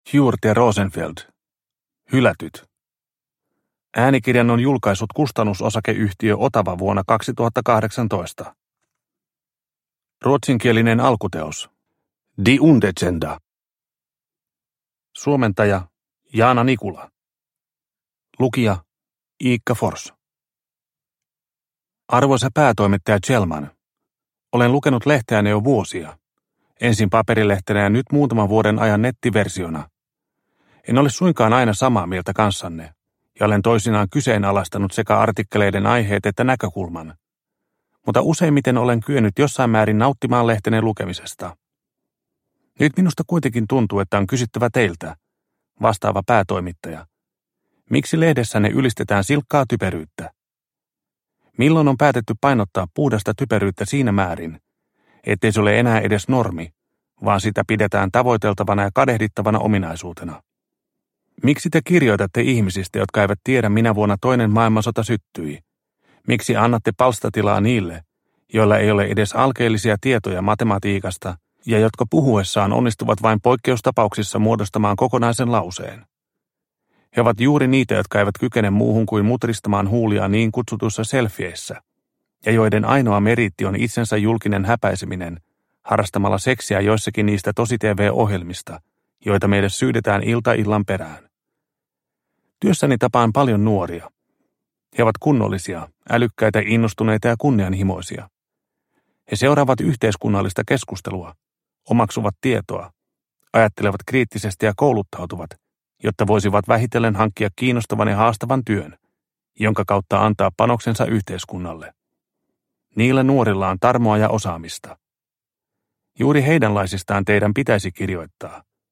Hylätyt – Ljudbok – Laddas ner